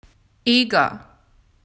pronounced /YEE-guh/.